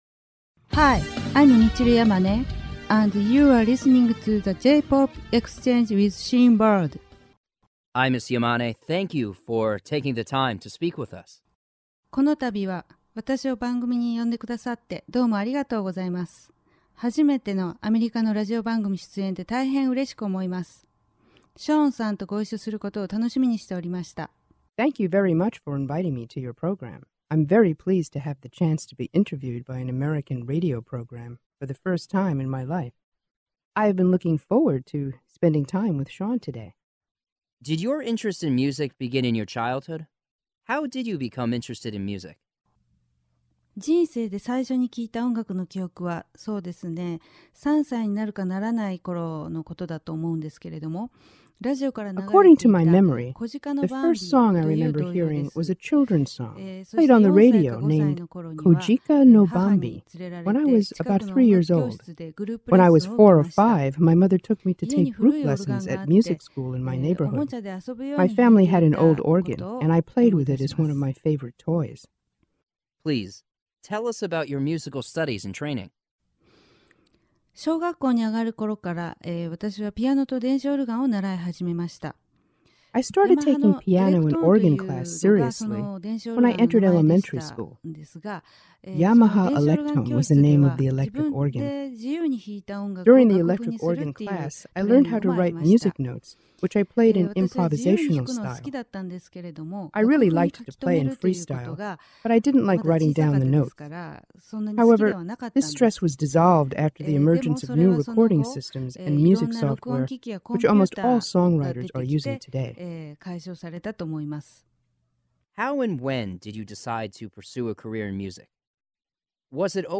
The J-Pop Exchange: Michiru Yamane Exclusive Interview